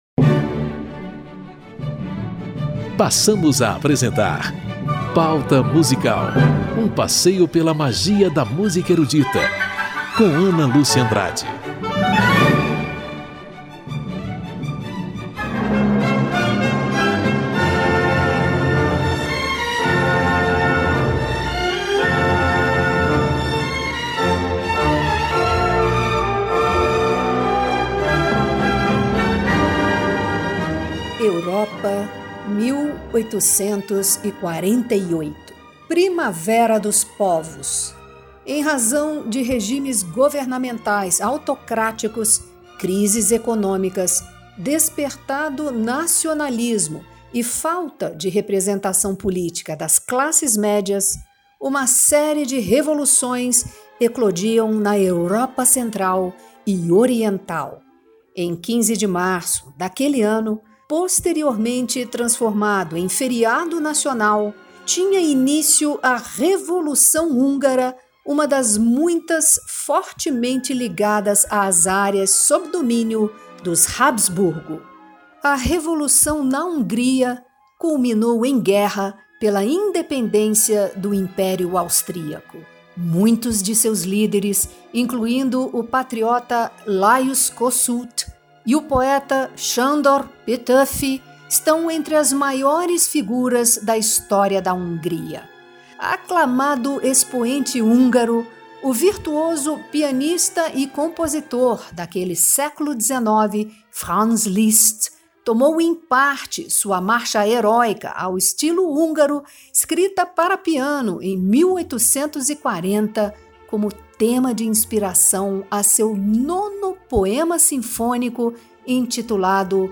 Três poemas sinfônicos de Franz Liszt.